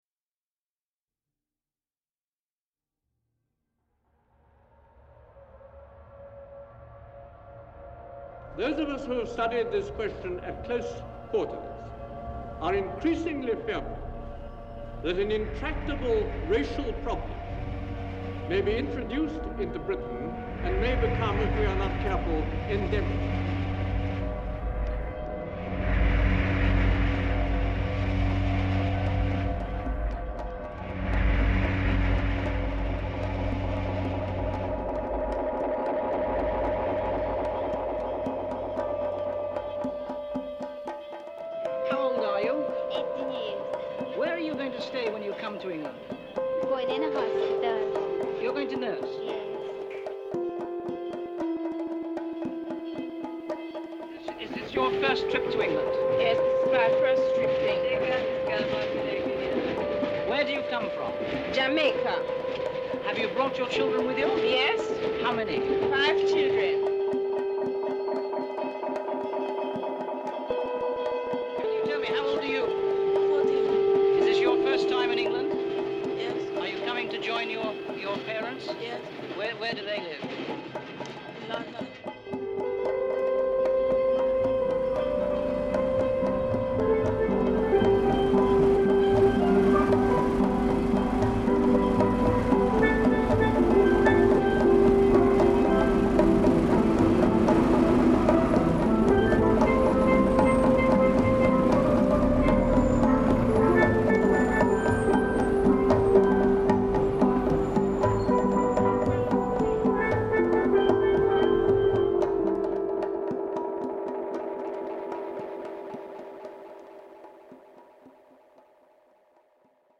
Steel drums in Brixton reimagined